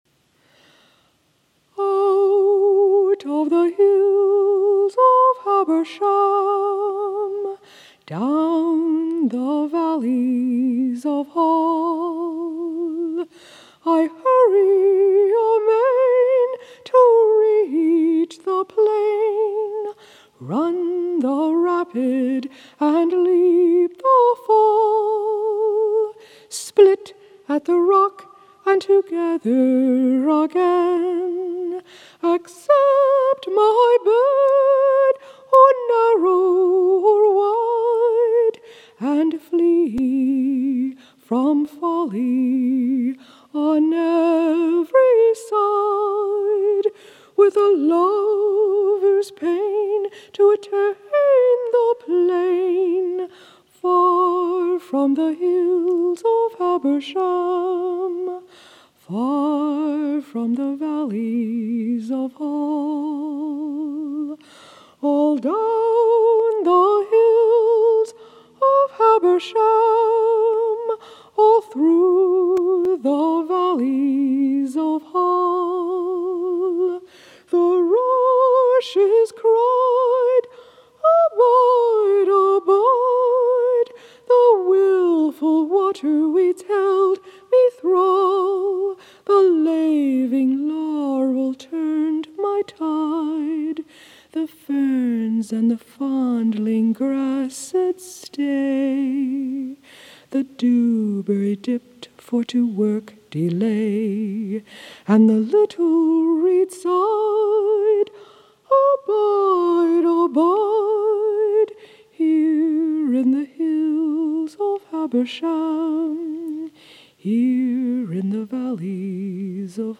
Her music is chant-like, but is
You are listening me sing the acapella version of my music to Cousin Sidney’s poem.